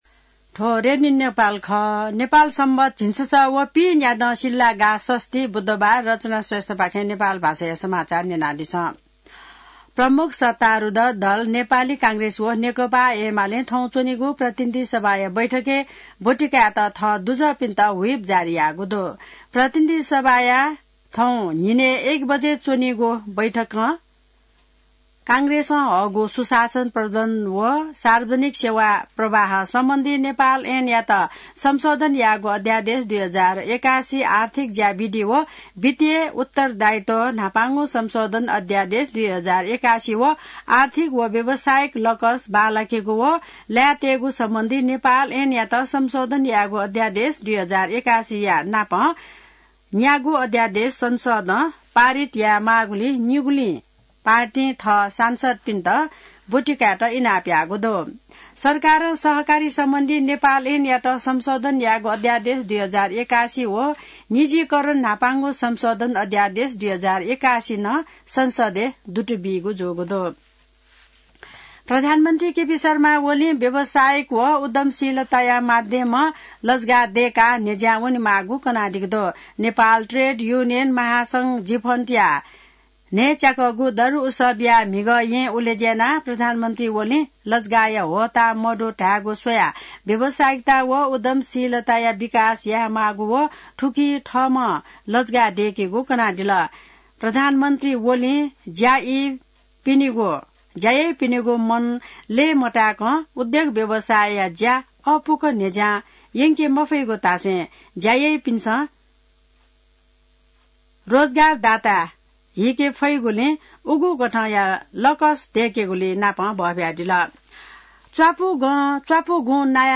नेपाल भाषामा समाचार : २२ फागुन , २०८१